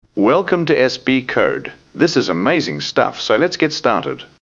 example 2 (man).mp3